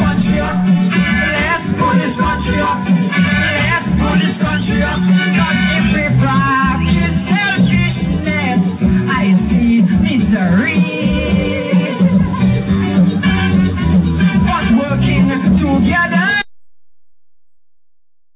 Click on these MEMORABLE CAISO SNIPETS(soon on DVD)